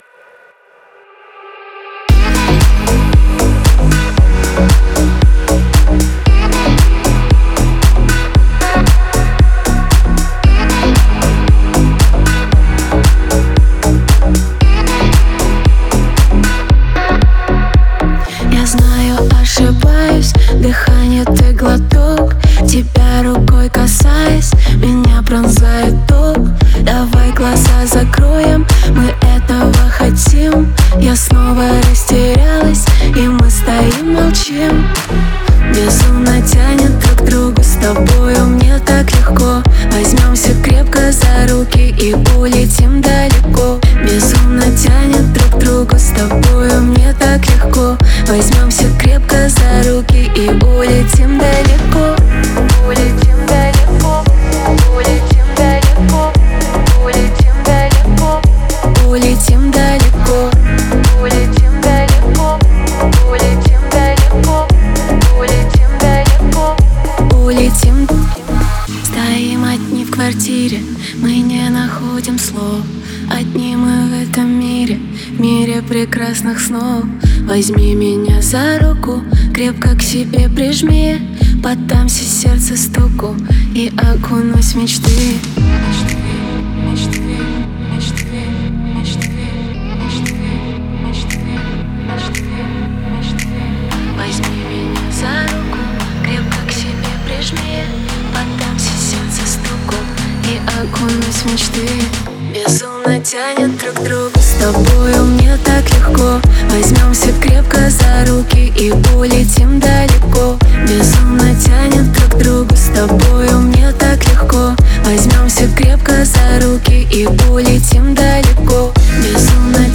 Файл в обменнике2 Myзыкa->Русская эстрада
Стиль: Deep House / Dance / Pop